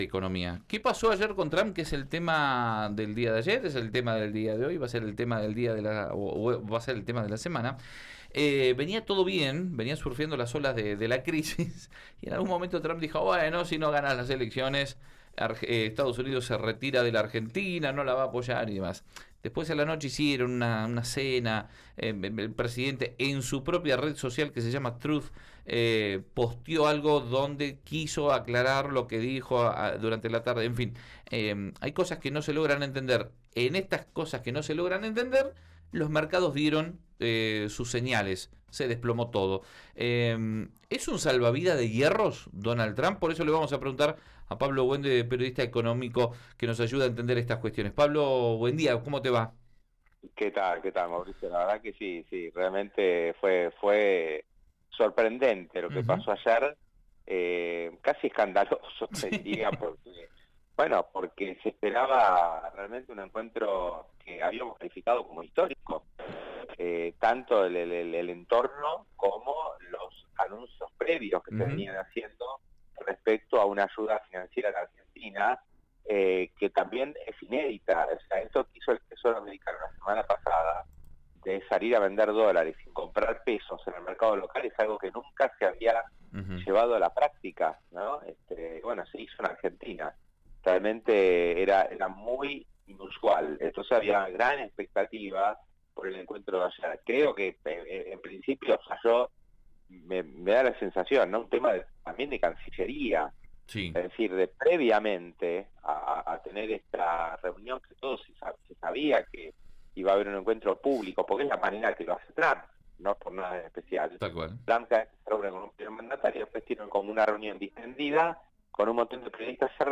columna económica en Río Negro Radio